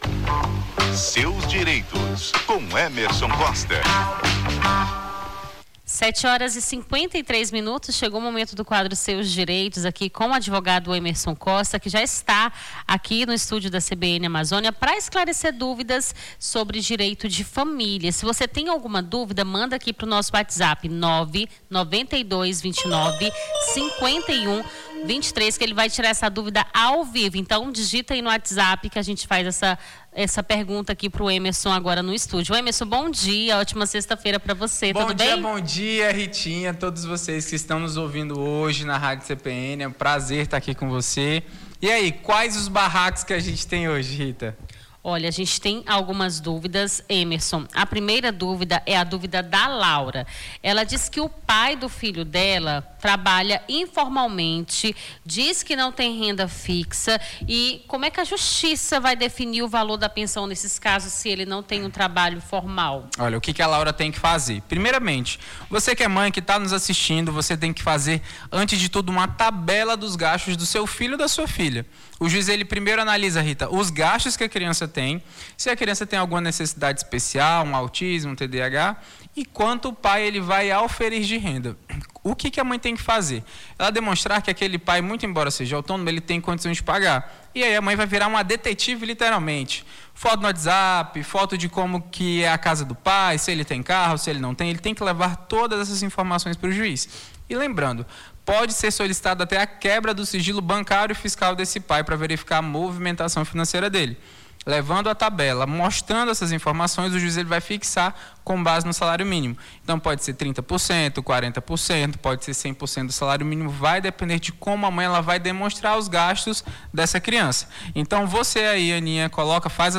Seus Direitos: advogado esclarece dúvidas sobre direito de família